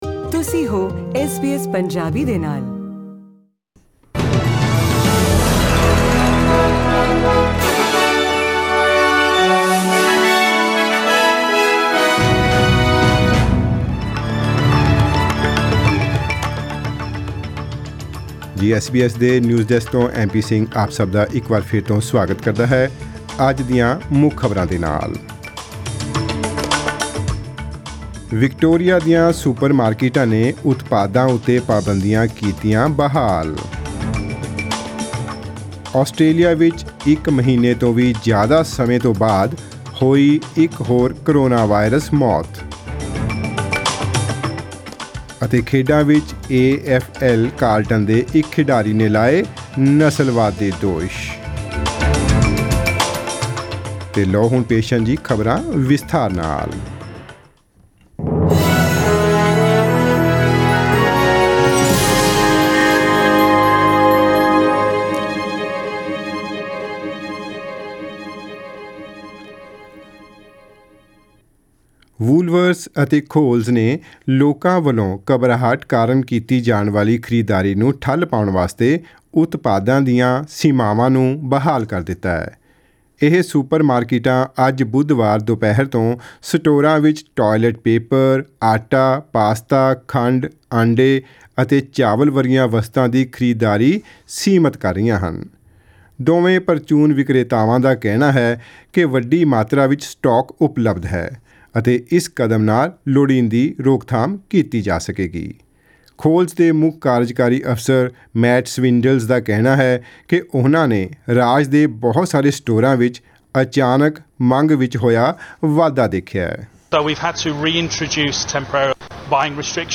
Australian News in Punjabi: 24 June 2020